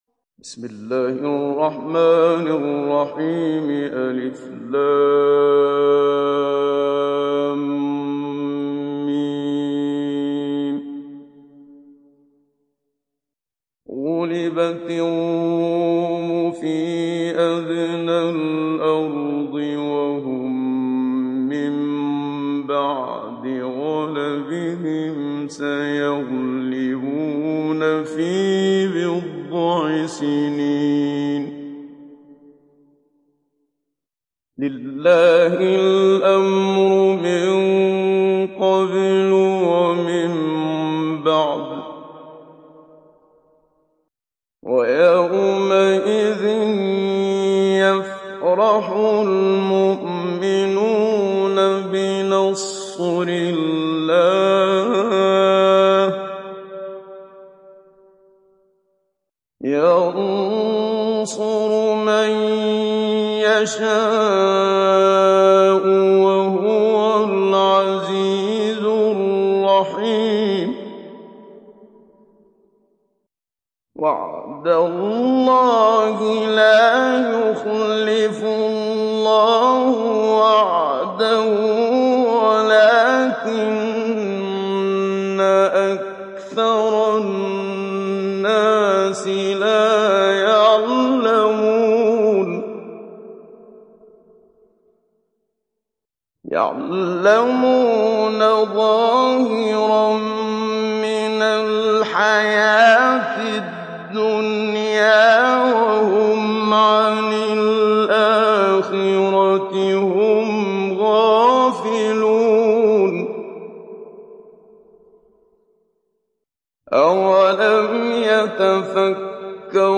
دانلود سوره الروم mp3 محمد صديق المنشاوي مجود روایت حفص از عاصم, قرآن را دانلود کنید و گوش کن mp3 ، لینک مستقیم کامل
دانلود سوره الروم محمد صديق المنشاوي مجود